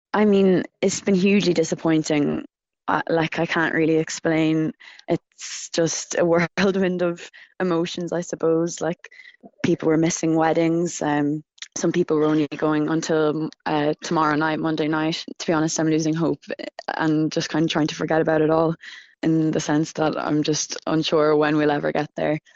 Irish woman